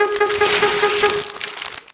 maildelete.wav